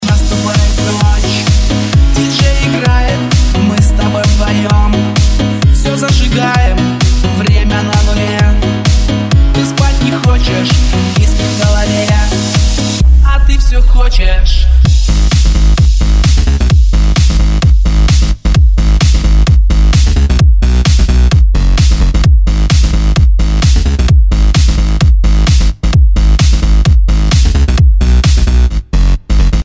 219 Категория: Клубные рингтоны Загрузил